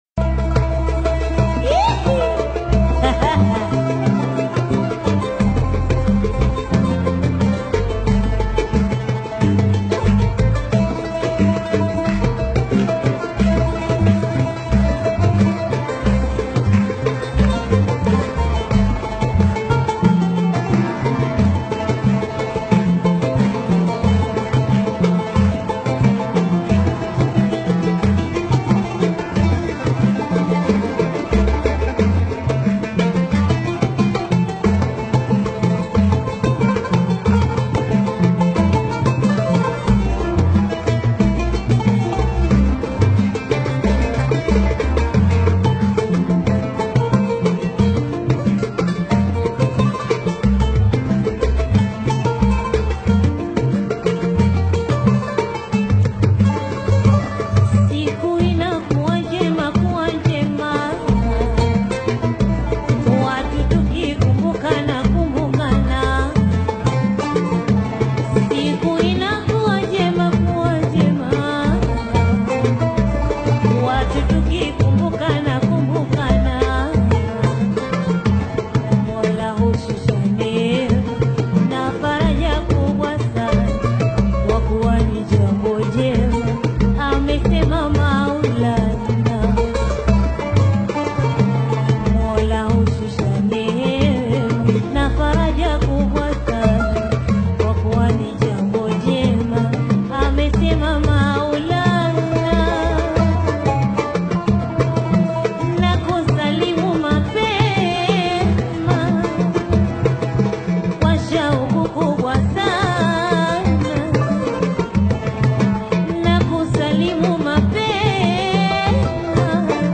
renowned artists in the taarab music scene